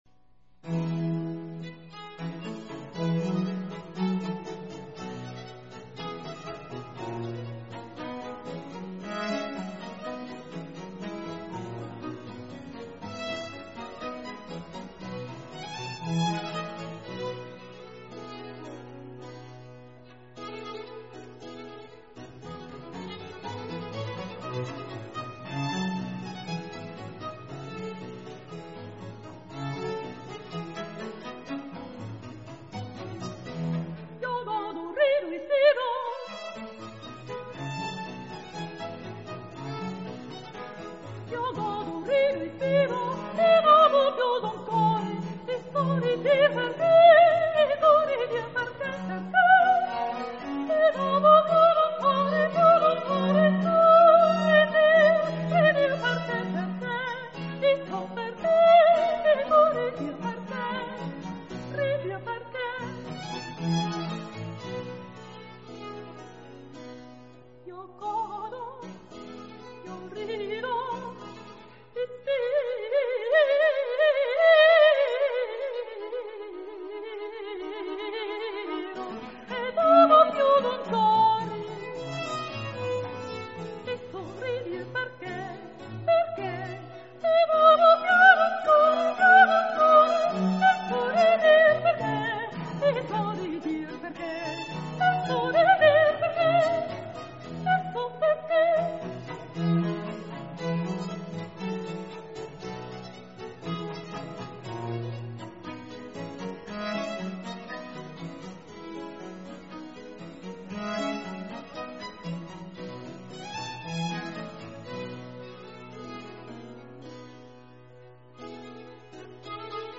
an aria
sung on this recording by a soprano